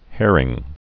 (hârĭng), Keith 1958-1990.